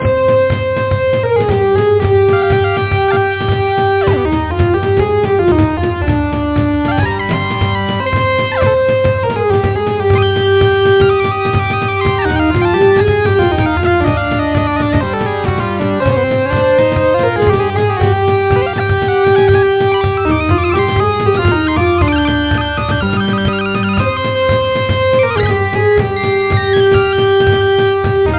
RumbleSqueak.mp3